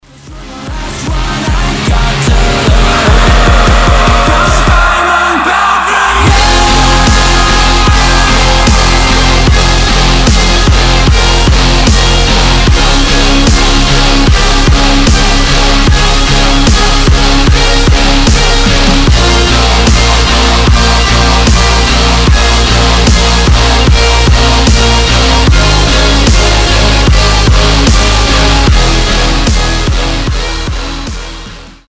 • Качество: 320, Stereo
громкие
жесткие
мощные
брутальные
Драйвовые
Metalcore
тяжелый рок